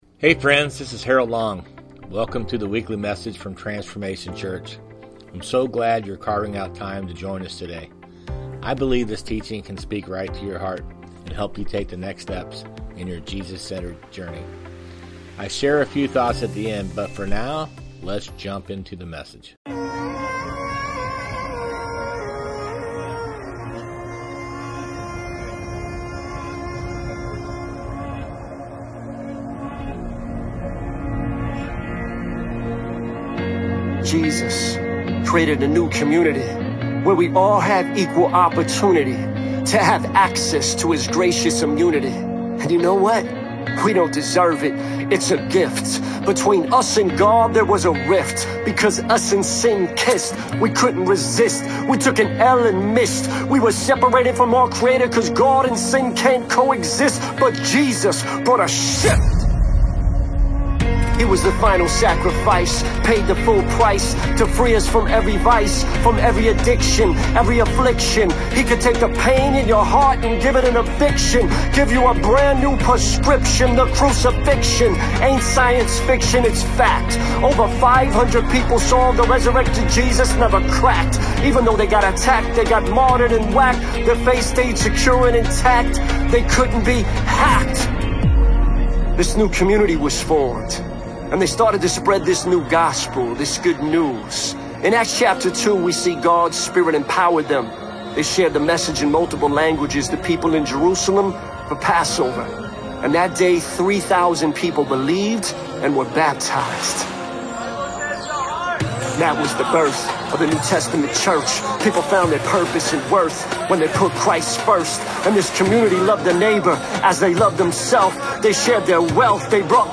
Sermons | Transformation Church